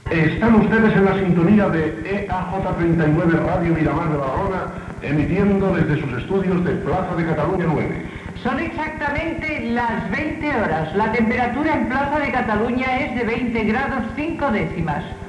Identificació com Radio Miramar de Badalona, hora i temperatura.